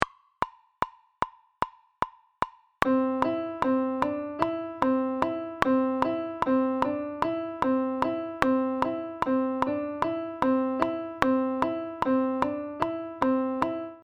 Ejemplo de compás de 7x8 con la medida de 2+3+2.
Imagen de elaboración propia. Subdivisión métrica del compás 7x8 en 2 + 3 + 2. (CC BY-NC-SA)
COMPAS-7x8-2.mp3